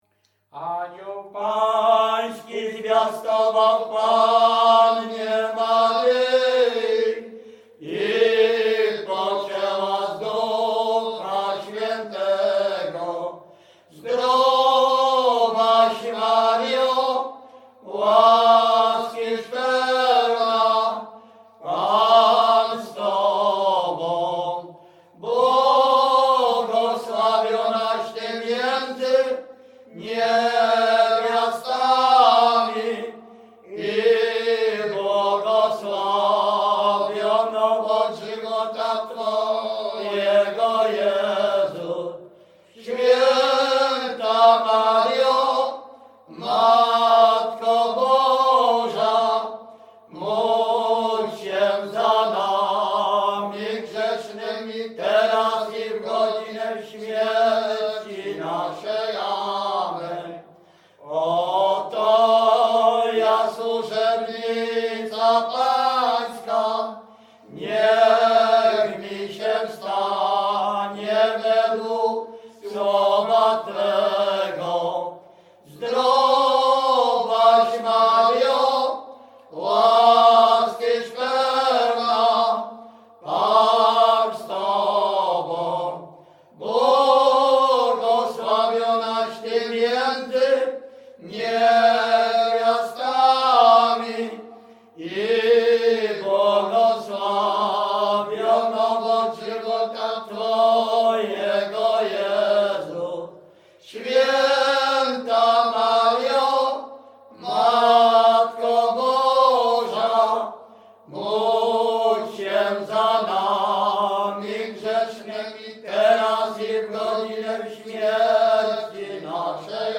Śpiewacy z Ruszkowa Pierwszego
Wielkopolska, powiat kolski, gmina Kościelec, wieś Ruszków Pierwszy
Array nabożne katolickie pogrzebowe maryjne modlitwy